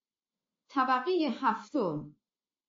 جلوه های صوتی
دانلود صدای طبقه هفتم از ساعد نیوز با لینک مستقیم و کیفیت بالا
برچسب: دانلود آهنگ های افکت صوتی اشیاء دانلود آلبوم صدای اعلام طبقات آسانسور از افکت صوتی اشیاء